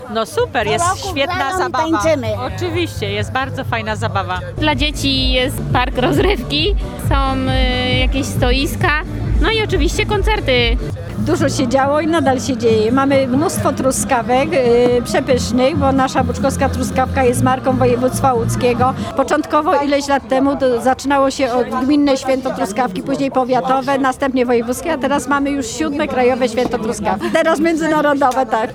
– Nasza truskawka jest marką województwa łódzkiego, trzeba jej spróbować – chwalą się panie z miejscowego koła gospodyń.